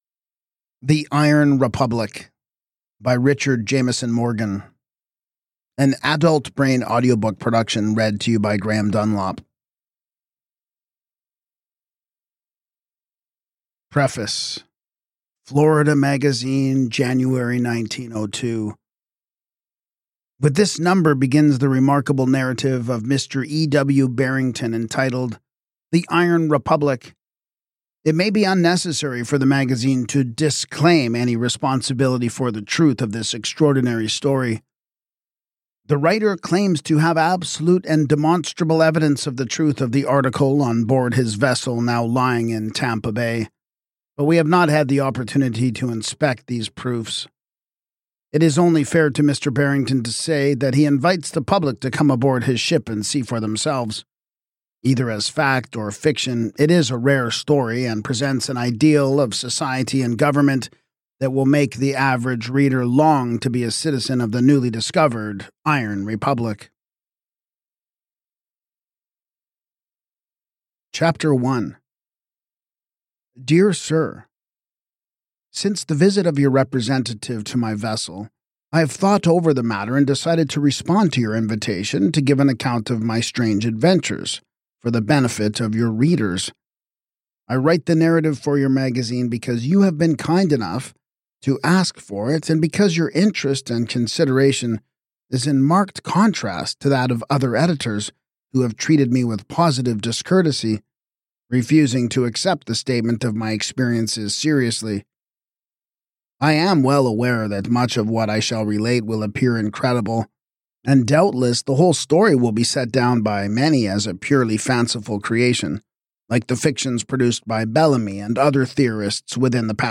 Narrated with Adultbrain’s signature clarity and depth, this audiobook resurrects a rare gem that speaks eerily to the disillusionment of our own age—where freedom is fading, truth is obscured, and adventure still calls from beyond the edge of the map.